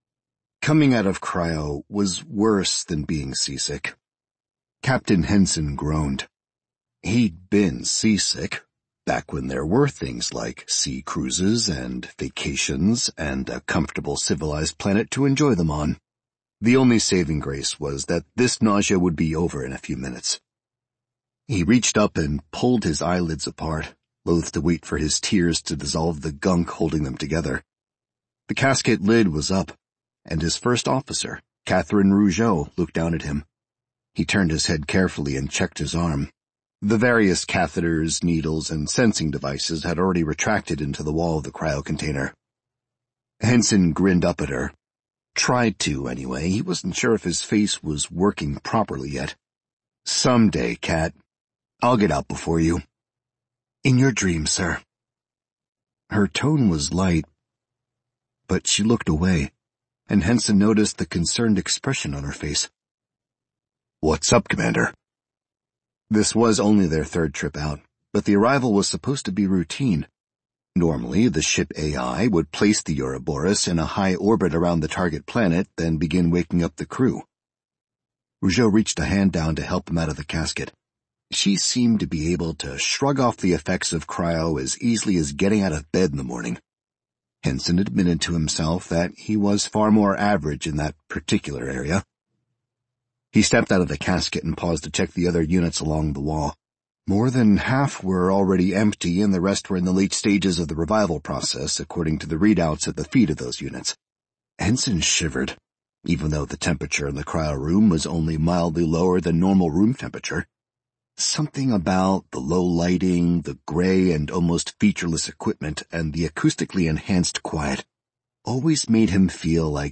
audiobook narration samplesdemos